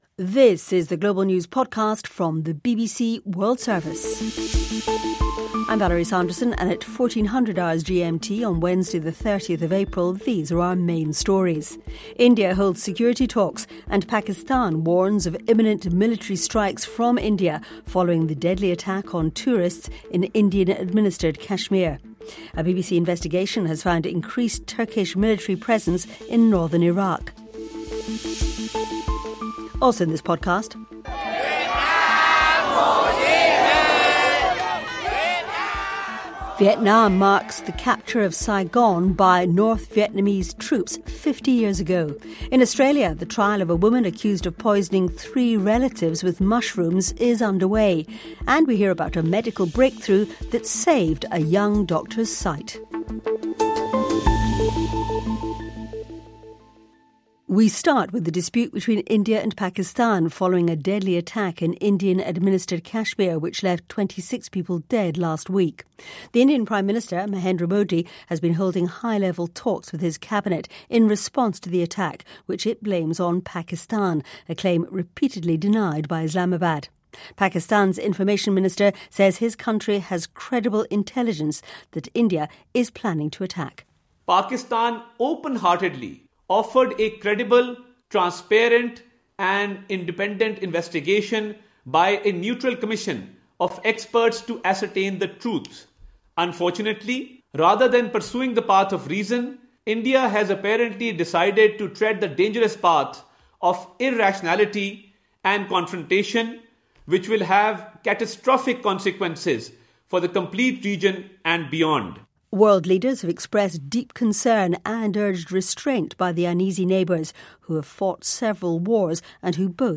BBC全球新闻